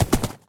horse
gallop3.ogg